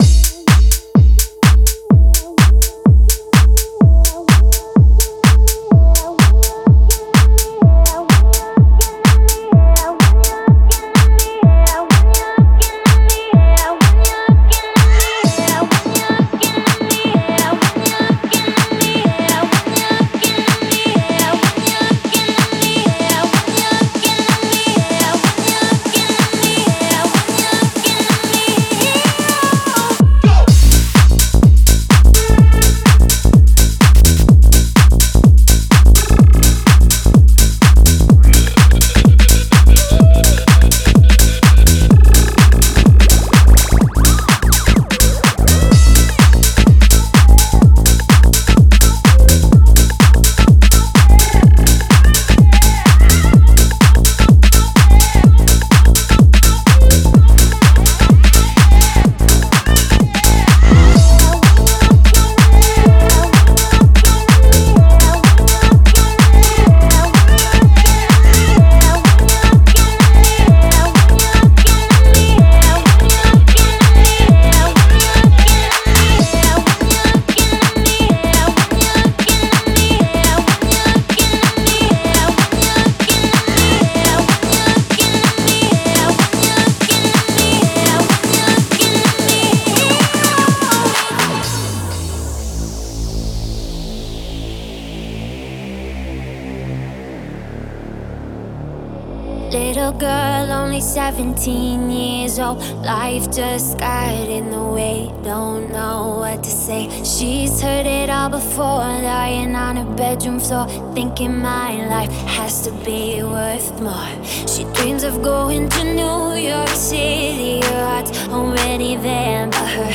это энергичный трек в жанре EDM